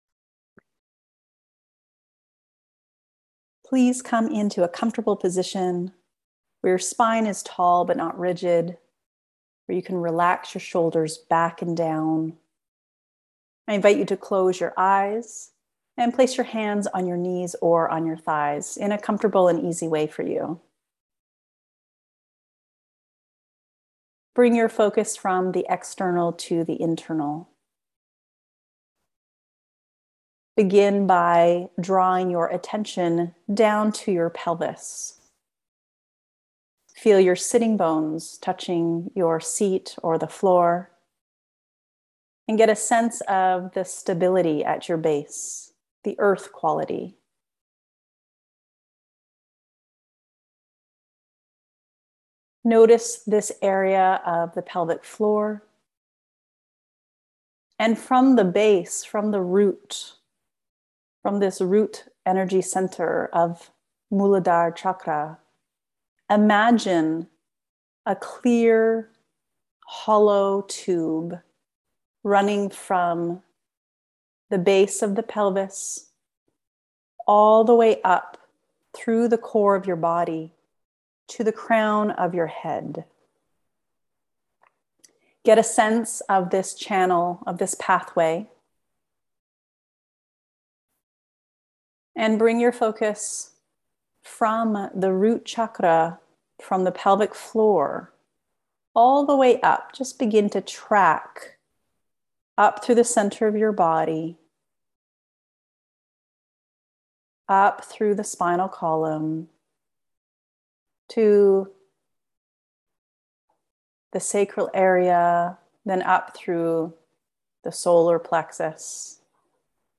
• Practice a meditation that connects your Root Chakra with your Crown chakra. This beautiful practice will help you to both anchor to earth, and open to your Divine connection, through the crown.
Root_Crown_Meditation1.mp3